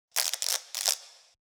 recharge.wav